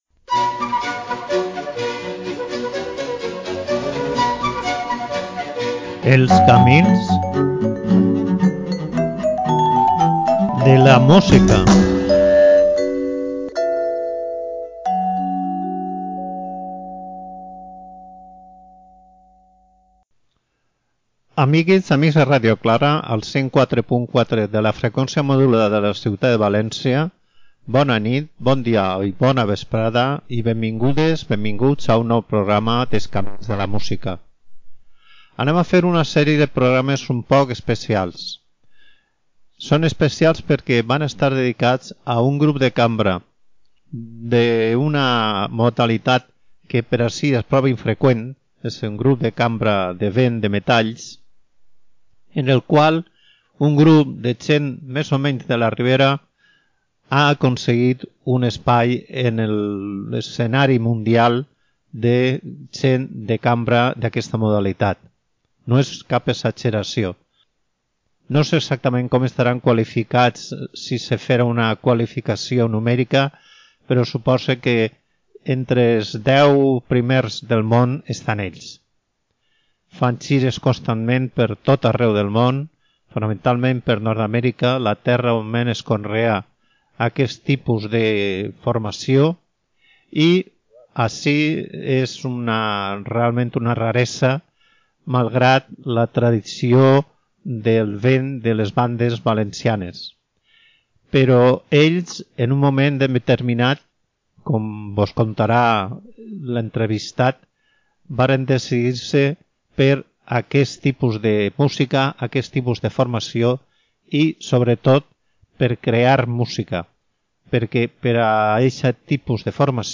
camins538-spanish-entrevista-1.mp3